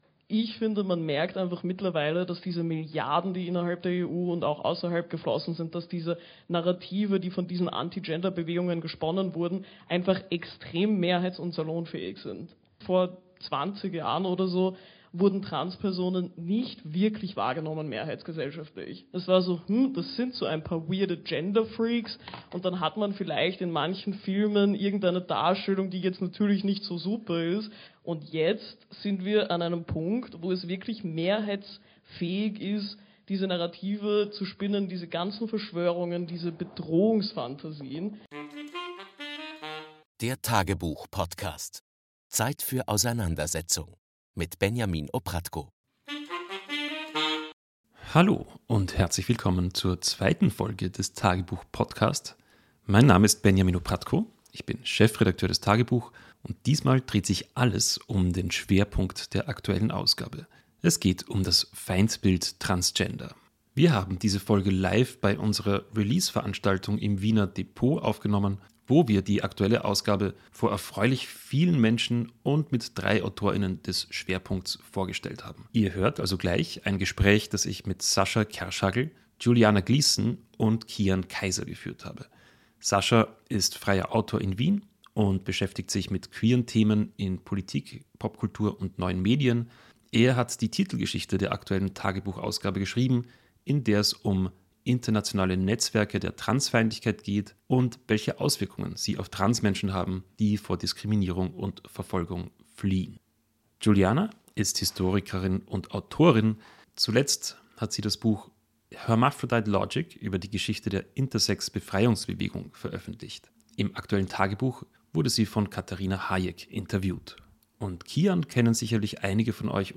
Die globale Rechte nimmt trans Menschen ins Visier und stößt damit in die politische Mitte vor. In diesem Gespräch gehen drei TAGEBUCH-Autor:innen aktuellen Formen und Folgen der Transfeindlichkeit nach. Warum ist gerade trans zu einem Schlüsselthema rechter Kulturkämpfe geworden?